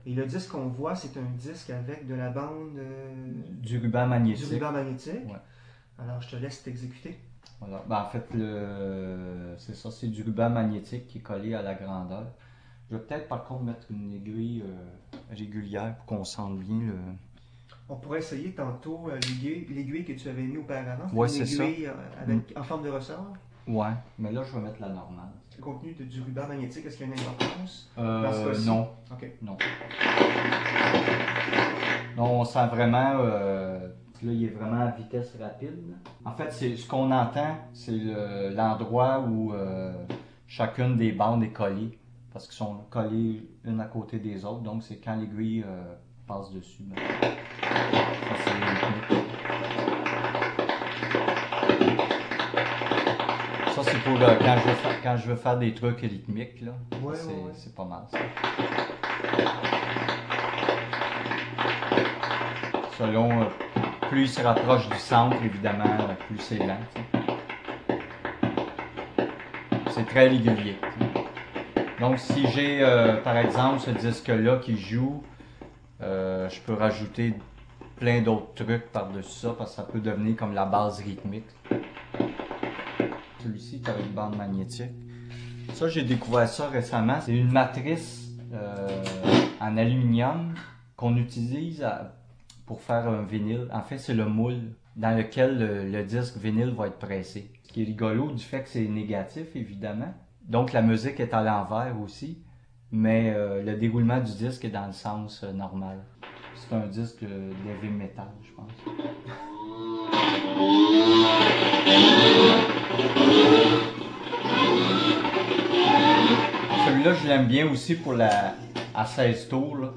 Résumé de l'entrevue :